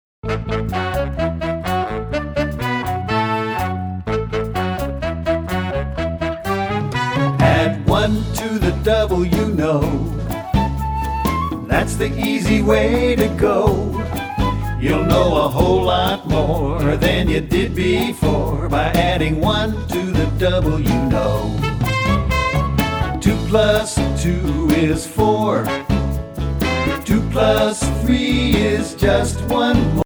- Mp3 Vocal Song Track